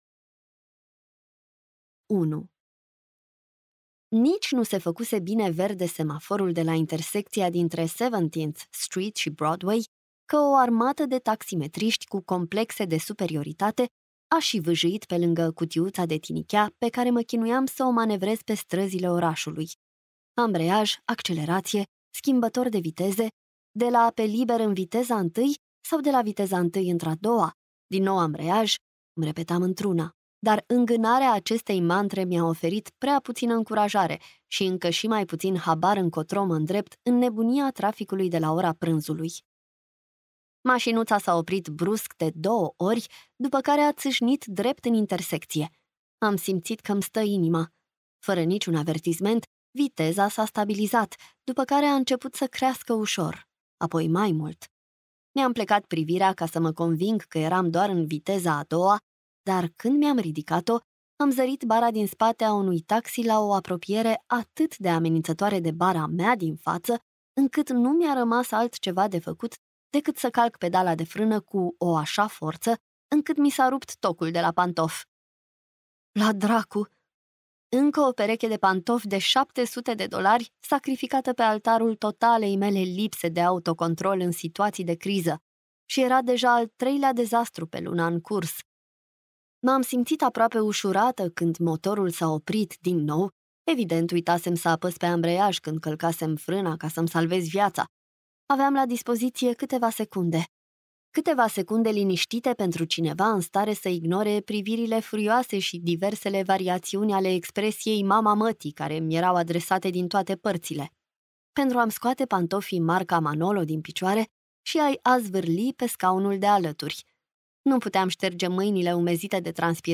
Ascultă un fragment Poți găsi audiobook-ul întreg aici: Cantitate Adaugă în coș Adaugă la Lista de Dorinte Adaugă pentru comparare Email 448 pagini 13 x 20 cm Softcover Leda Bazaar vezi toate detaliile